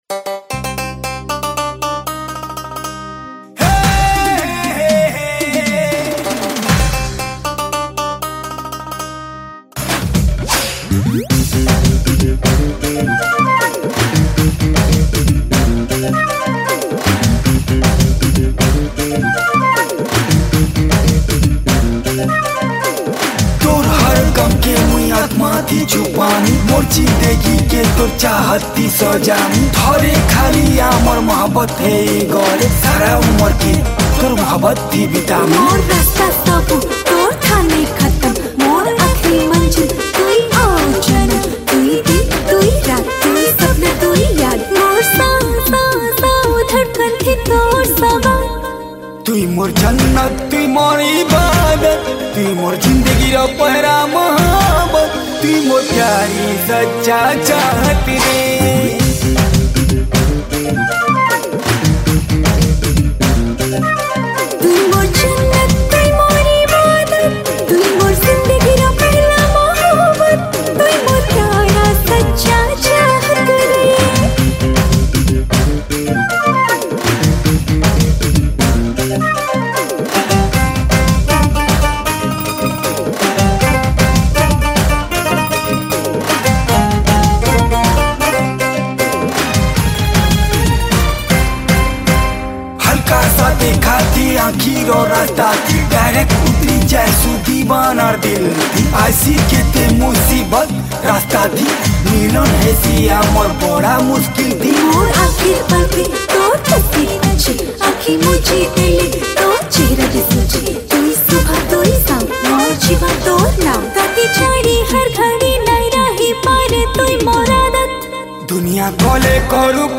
Sambalpuri Song